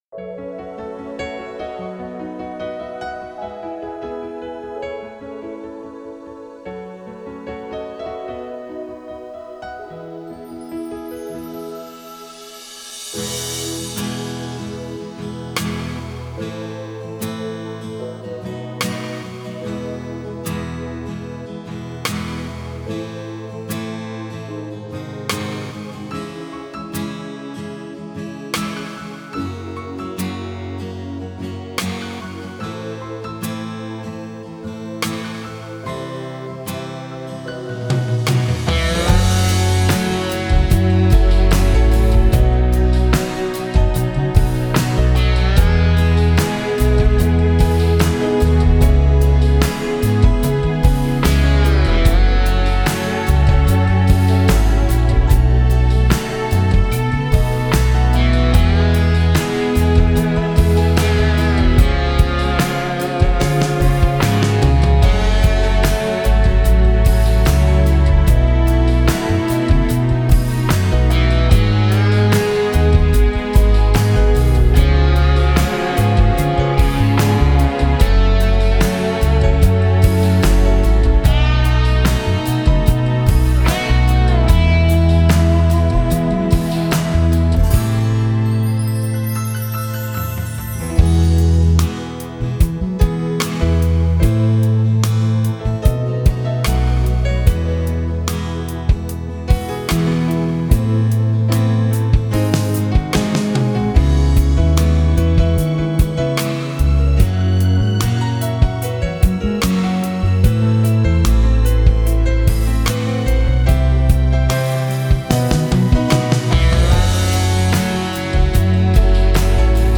• Жанр: Детские песни
Слушать Минус